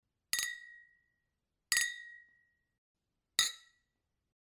Звуки чоканья бокалов